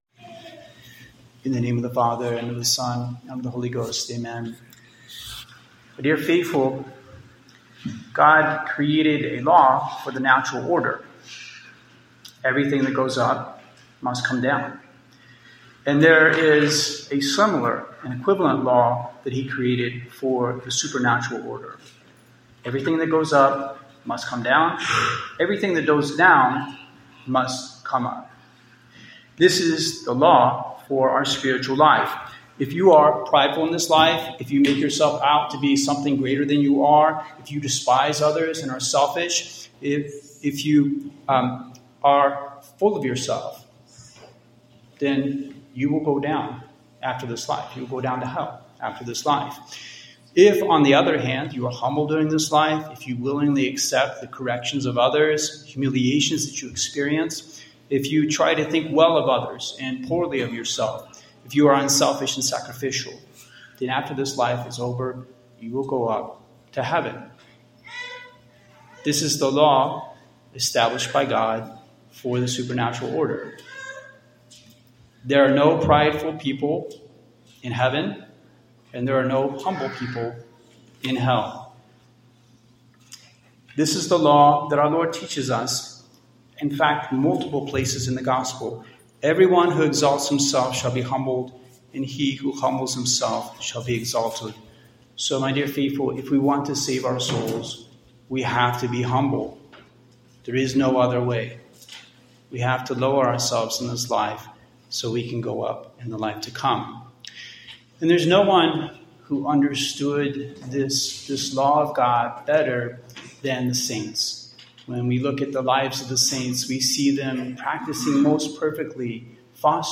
Taking the Last Place, Sermon